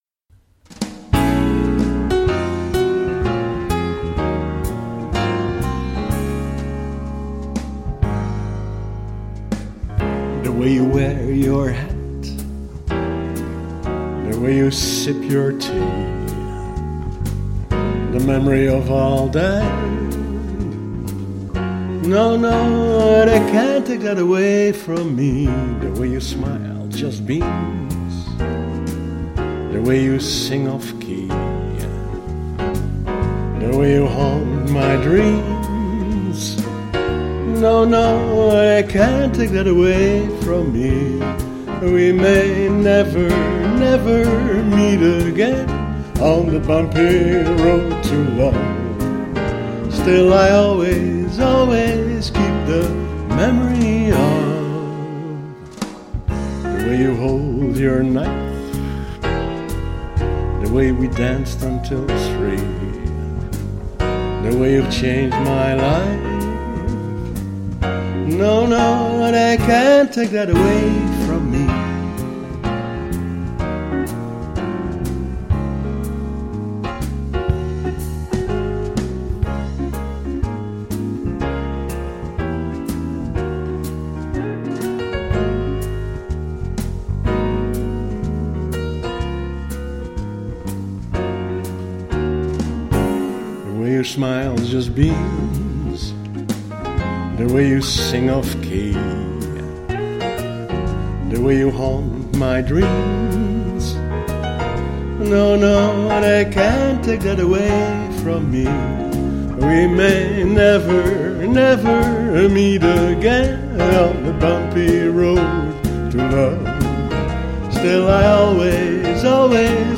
swing